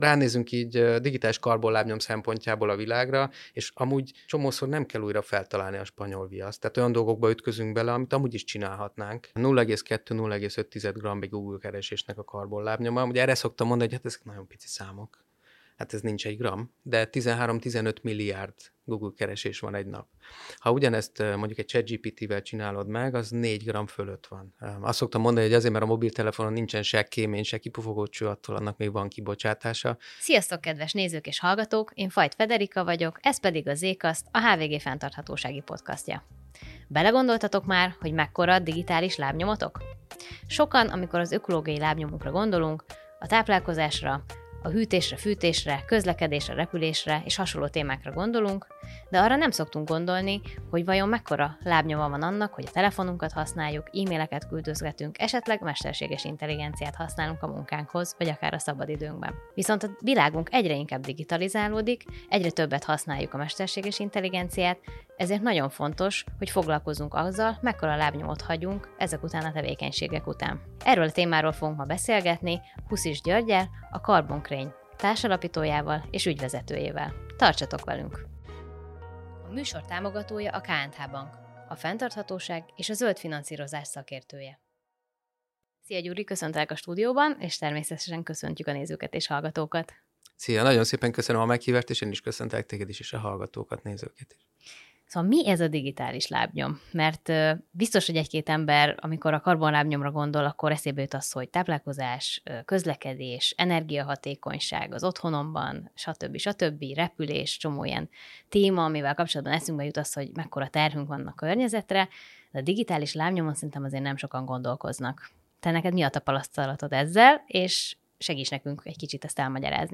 Az heti nagyinterjúban